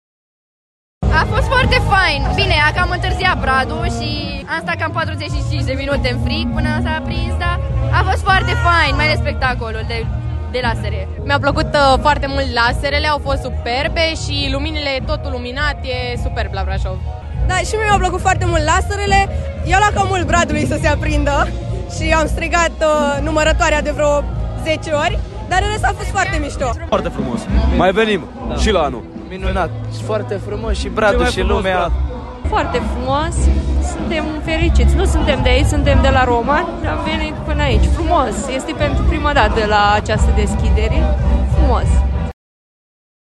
Primarul Brașovului, Allen Coliban: